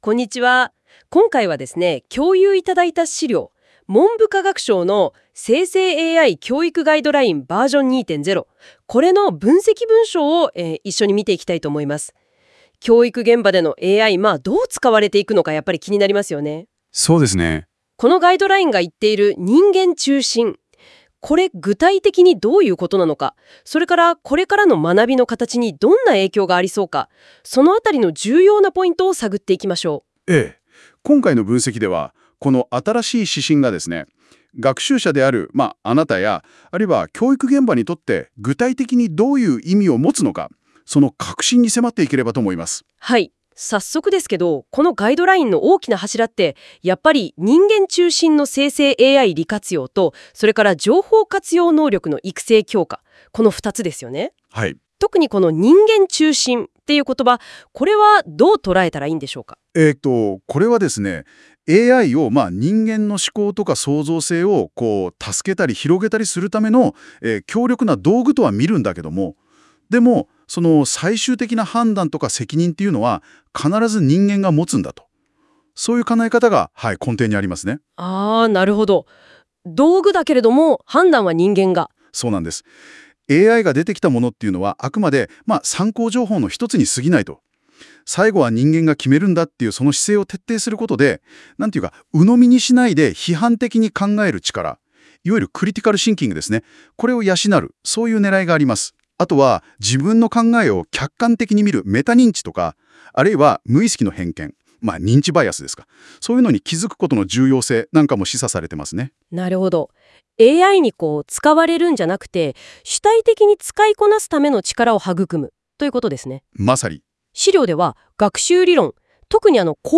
🎧 この記事の解説音声
忙しい方のために、この記事をポッドキャスト形式で解説しています。
※ この音声は Google NotebookLM を使用して記事内容から生成されています。